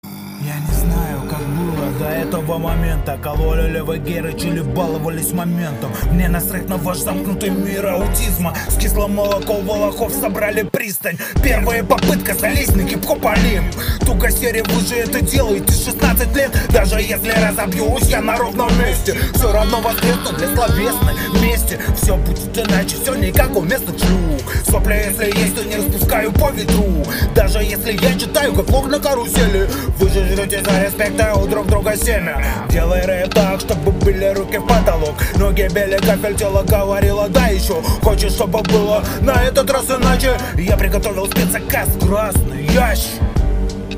Голос сильный, но текстом убиваешь весь эффект, меньше копро-тем